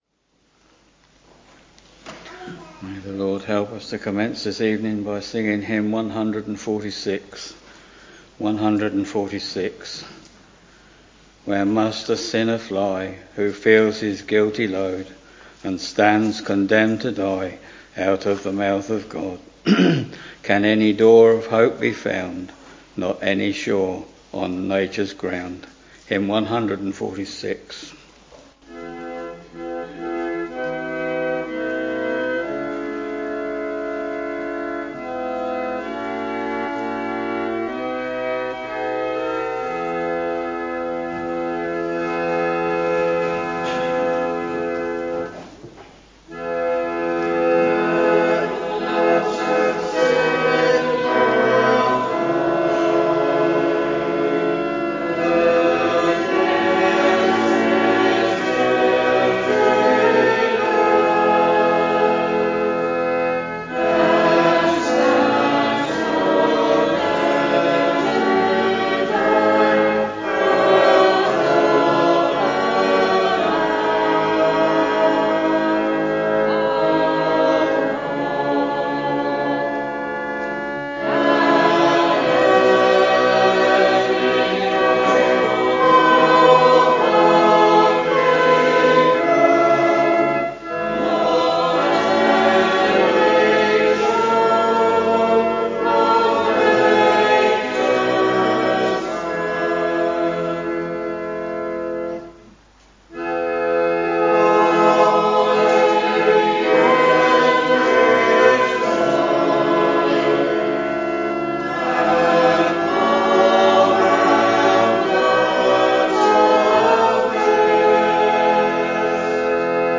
We are very pleased for you to listen to the live or archived services if you are not able to assemble for public worship in your local church or chapel.